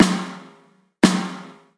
螺号修复 - 声音 - 淘声网 - 免费音效素材资源|视频游戏配乐下载
从非常糟糕的小鼓到经过严重的eqing、压缩和合成器白噪声的改进的声音，